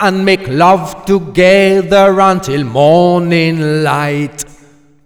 OLDRAGGA6 -L.wav